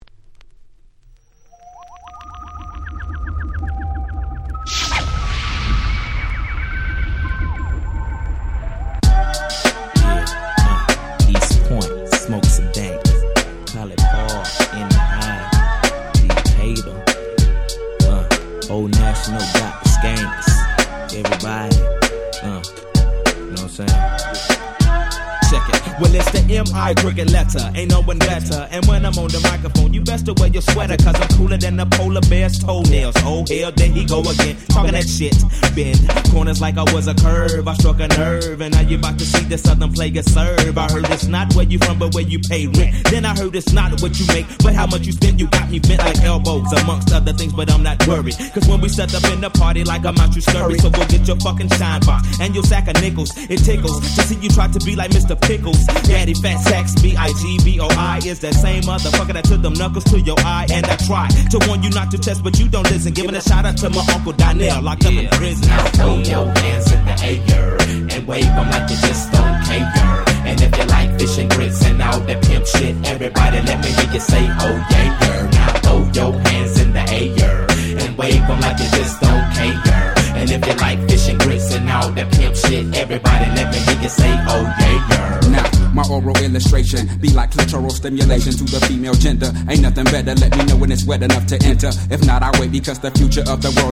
96' Smash Hit Hip Hop !!
爽快なフロアチューンです！